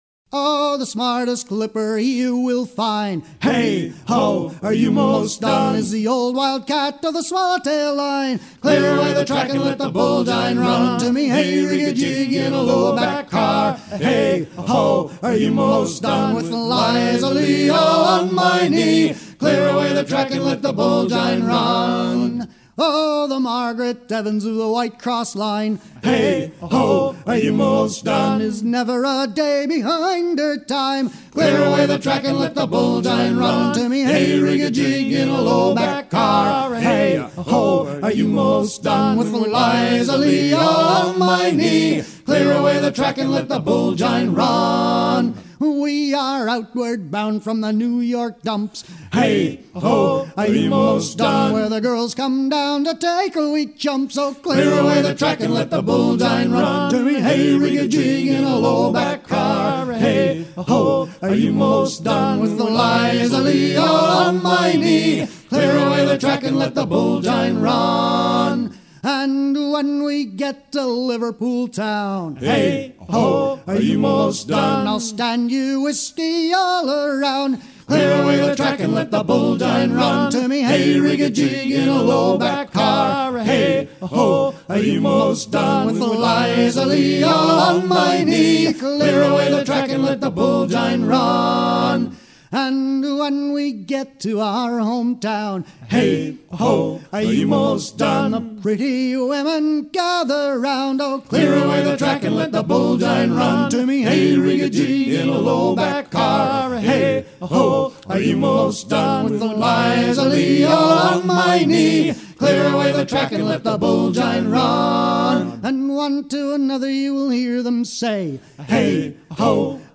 à virer au cabestan
circonstance : maritimes
Pièce musicale éditée